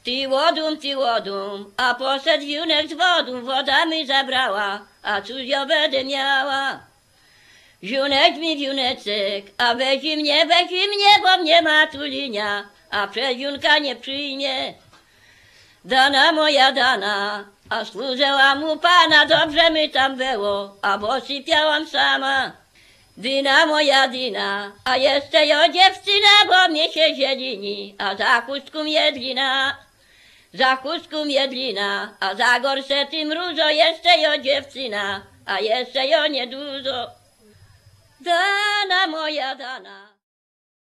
Przy¶piewki (Radzice Małe, Opoczyńskie 1987)
Badania terenowe
¶piew